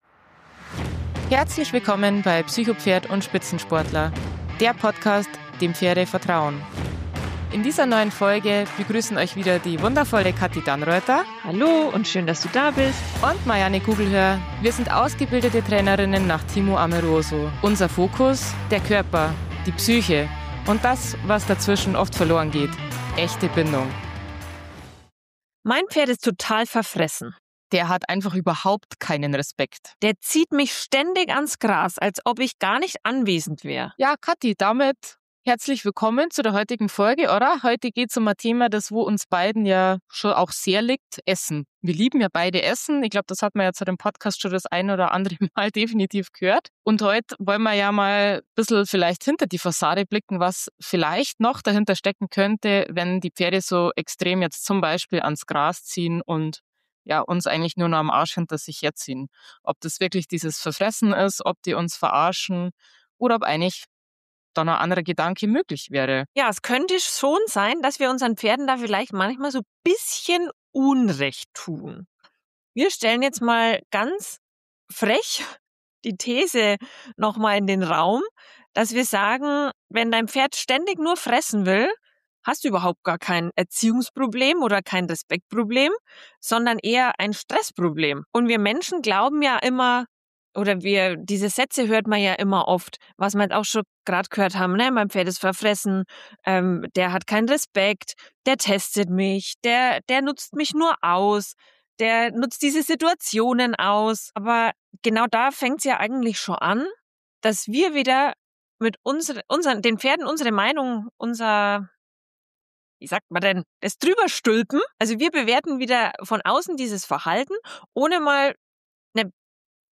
Freut euch auf ein ehrliches, provokantes und tiefgehendes Gespräch, das euch eine völlig neue Perspektive auf Futterverhalten gibt und euch hilft, euer Pferd wirklich zu verstehen.